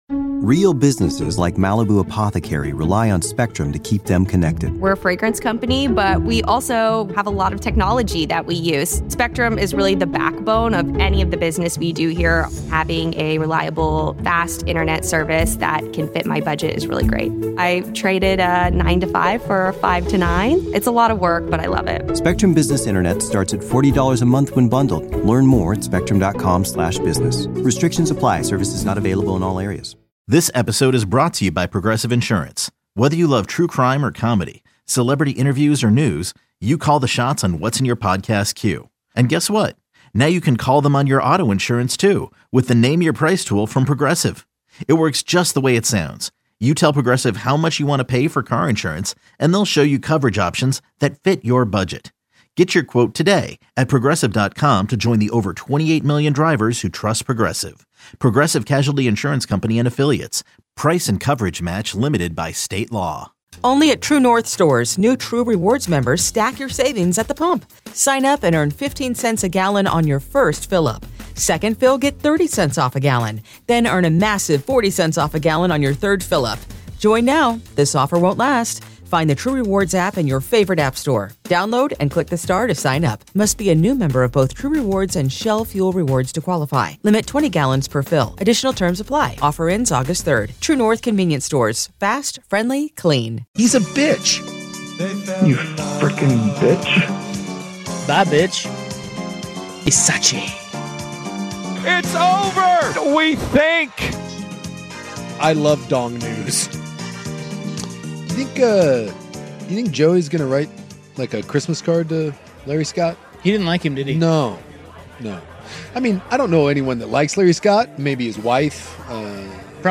Portland's iconic sports talk show.